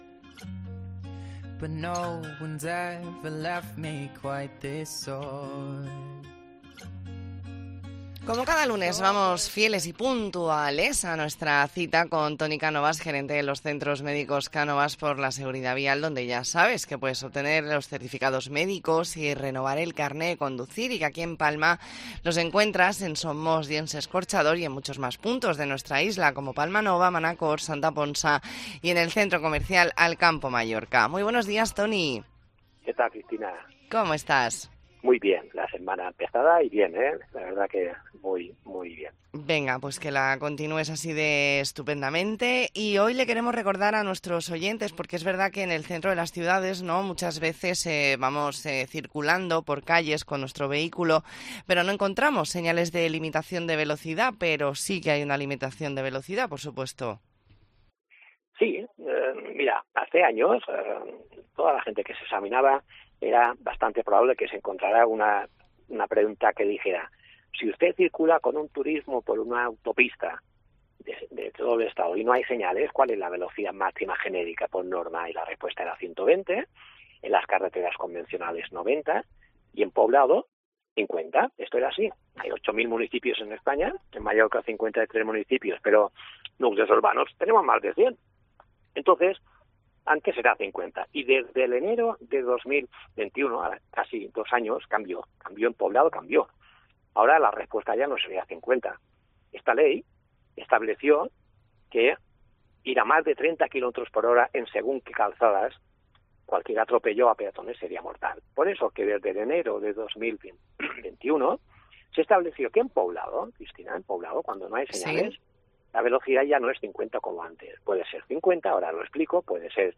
Entrevista en La Mañana en COPE Más Mallorca, lunes 9 de octubre de 2021.